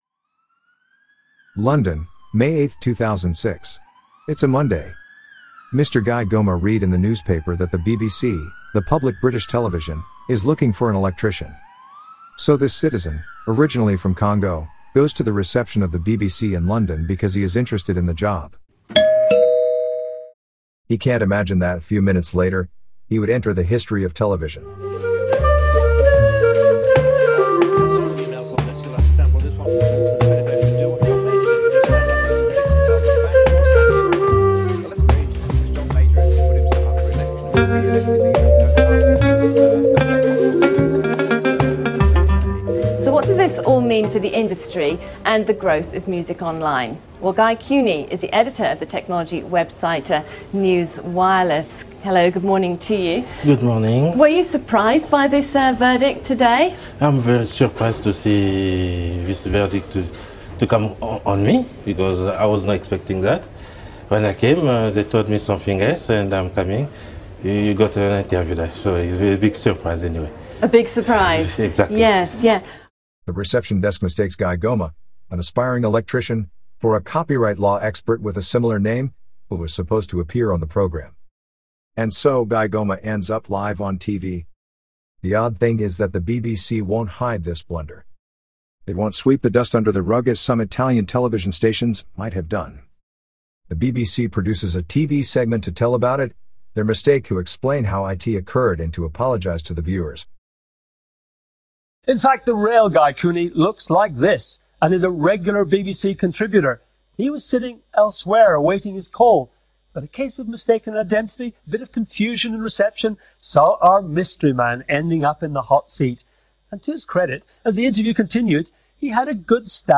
(Translation from Italian to English by AI. Musics and effects by PIXABAY.